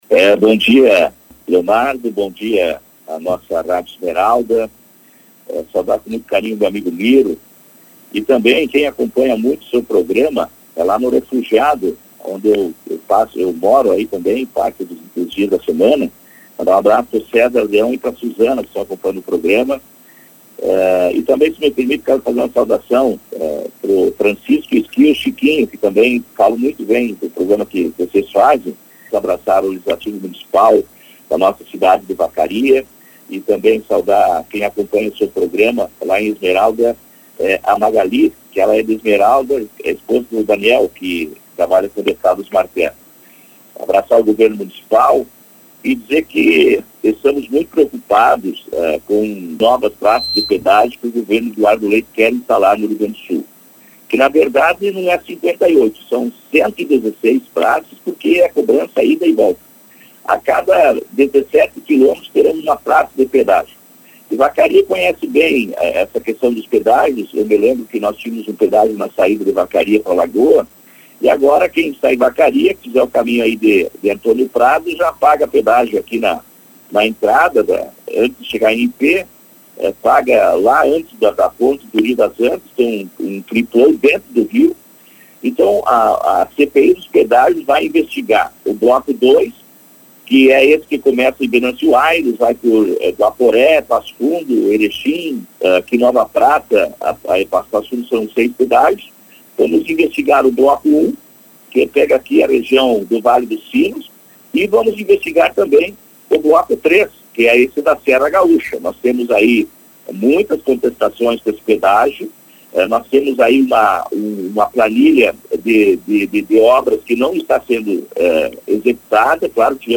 Em entrevista à Rádio Esmeralda, Paparico considerou a instalação da comissão como propositiva, com objetivo de trazer para o debate as propostas do governo do Estado dentro do Programa RS Parcerias.